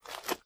STEPS Dirt, Walk 14.wav